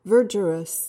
PRONUNCIATION:
(VUHR-juh-ruhs)